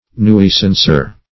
Nuisancer \Nui"san*cer\, n.